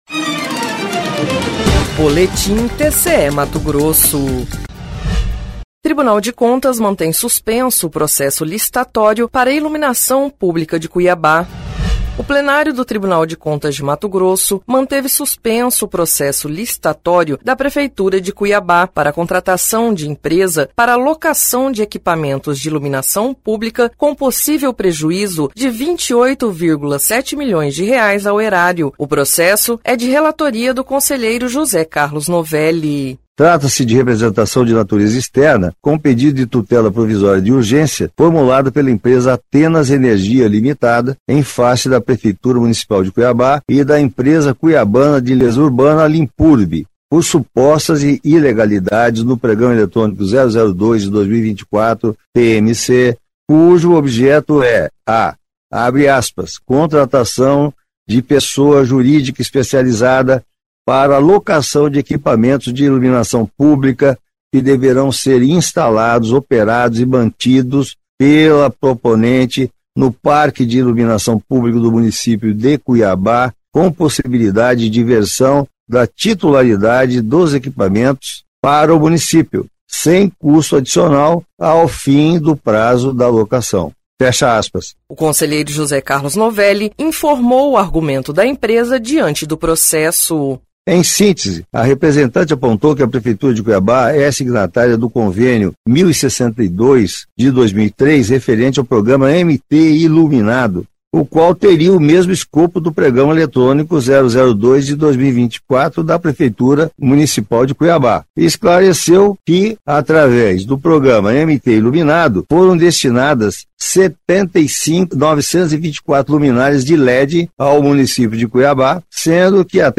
Sonora: José Carlos Novelli – conselheiro do TCE-MT